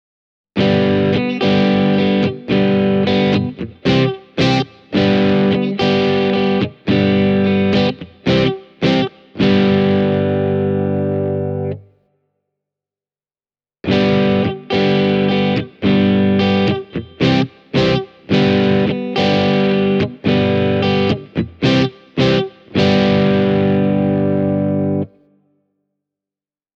Äänitin koesarjan eri kitaroilla Blackstar HT-1R -putkikombolla niin, että jokaisessa klipissä ensimmäinen puolisko on äänitetty pelkästään Whirlwind-johdolla ja sitten toisessa on lisätty Spin X -johto signaalitiehen.
Hamer USA Studio Custom:
Spin X:n kanssa soundissa on vähemmän raapivaa terävyyttä diskantissa, enemmän avoimuutta ja kiiltoa ylä-middlen preesensalueella, sekä tiukempi ja tarkempi bassotoisto.
spin-x-cable-e28093-hamer-amp.mp3